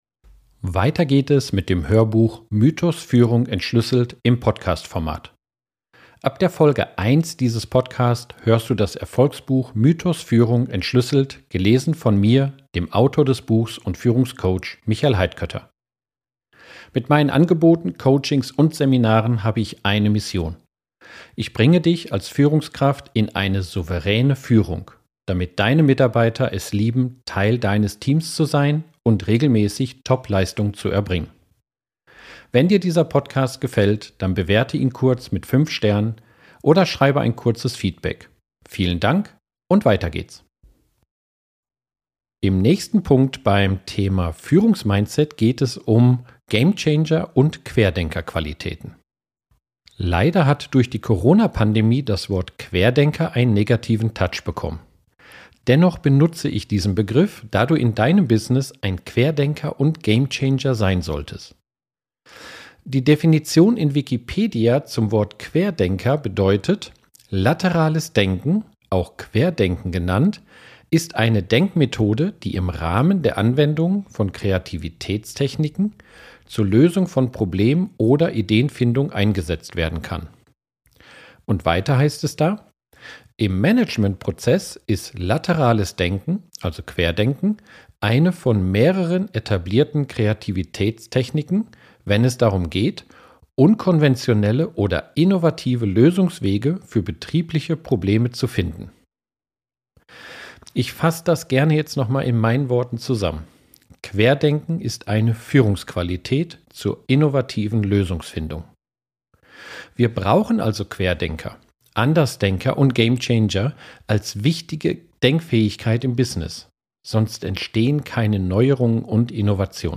Du erhältst das vollständige Hörbuch gratis und ganz bequem im